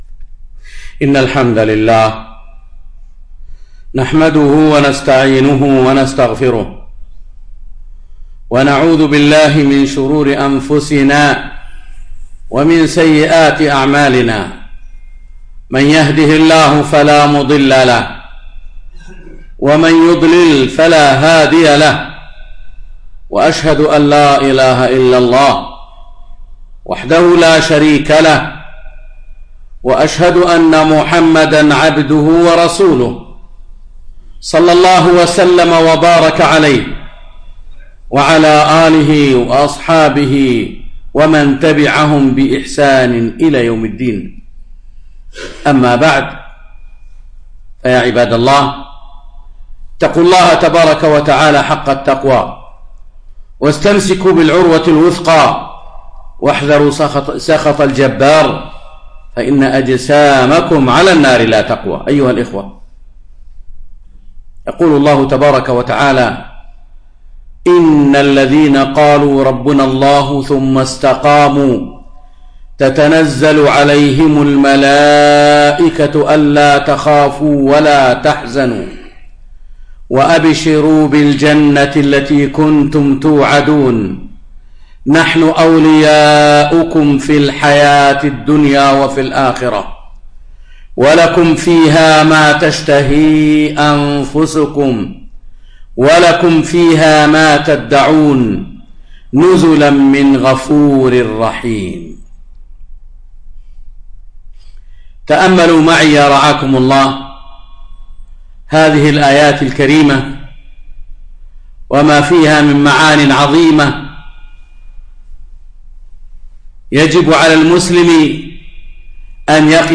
خطبة - الإستقامة